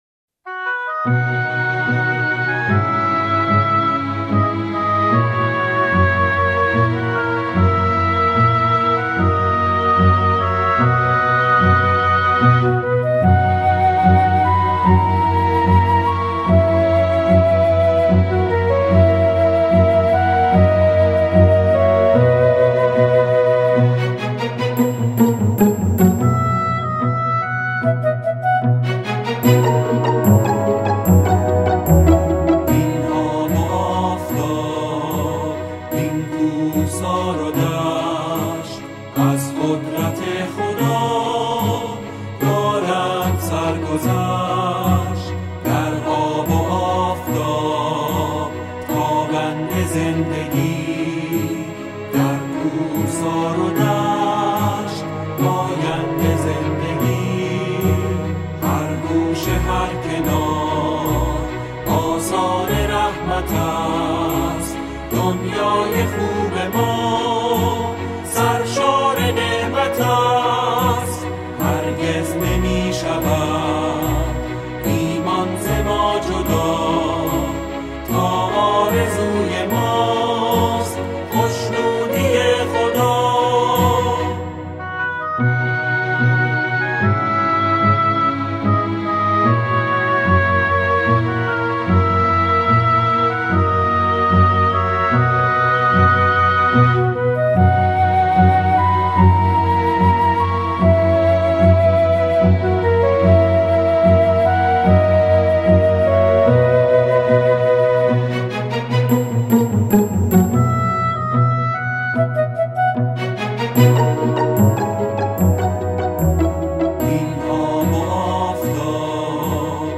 اعضای گروه کر